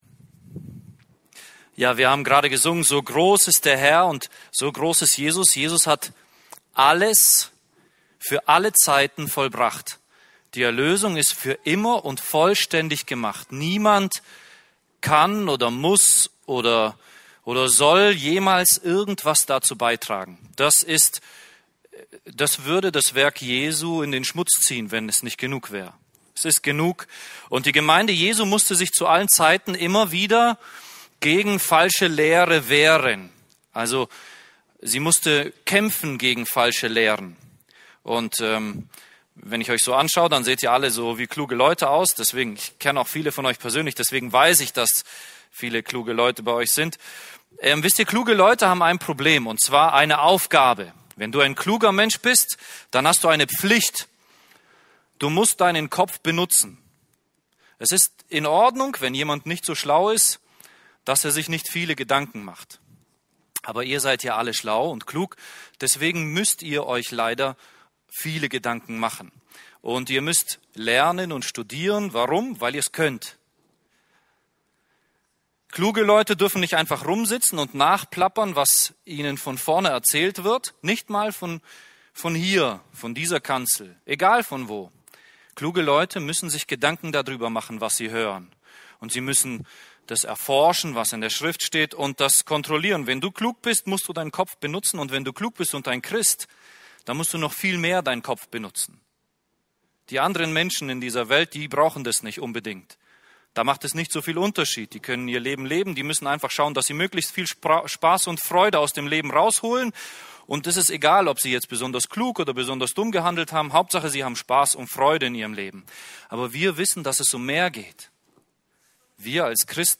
für uns Dienstart: Bibelstunden « Gott baut sich ein Zuhause